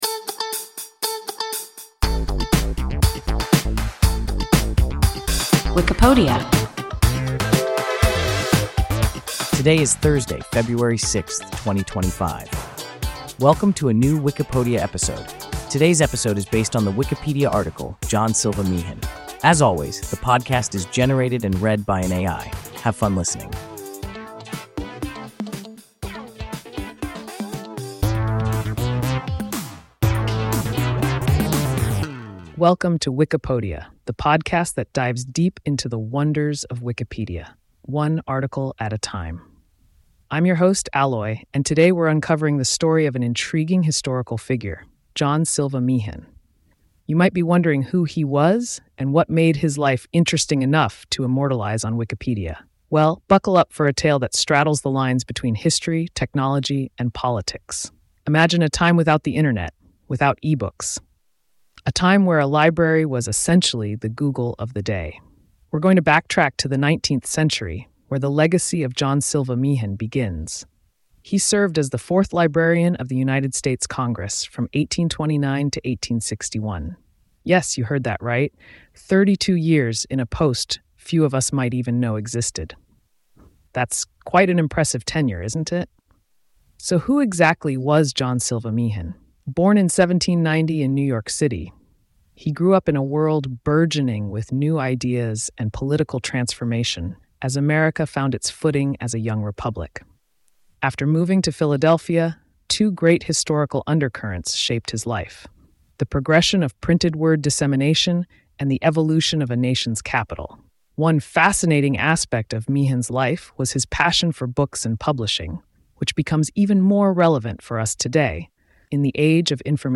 John Silva Meehan – WIKIPODIA – ein KI Podcast